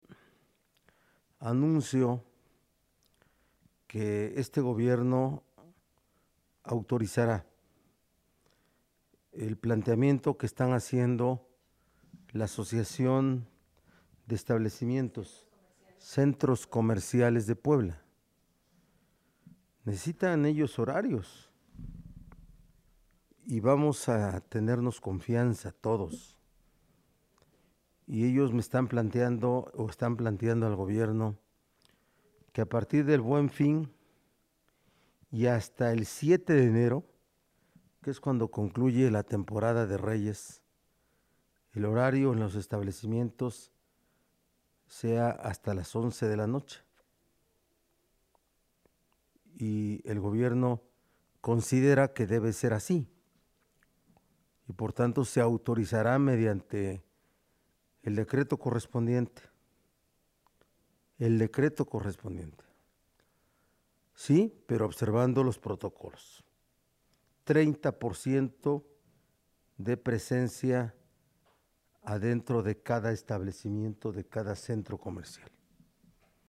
En videoconferencia de prensa en Casa Aguayo, el mandatario señaló que esta decisión es producto del diálogo con representantes de las plazas comerciales para seguir reactivando la economía con las medidas preventivas correspondientes.